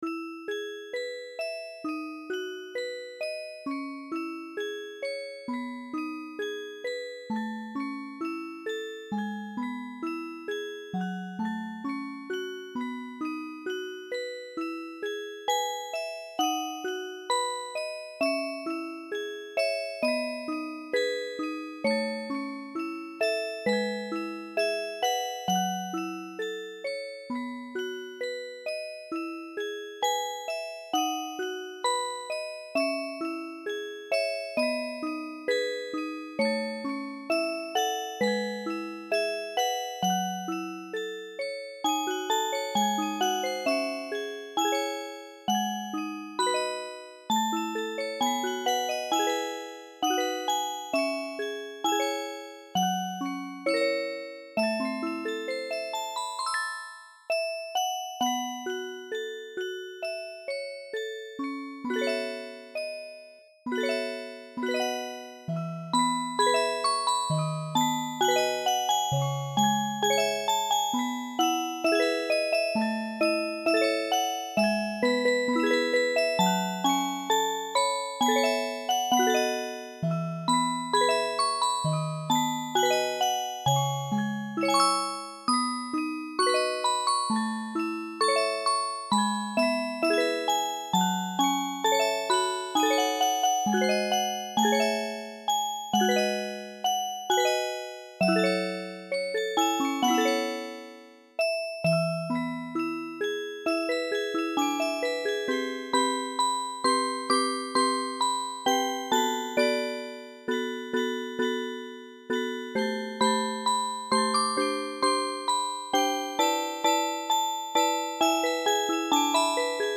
ですから、このバージョンでは歌は入っていませんし、長さも1コーラス分しかありません。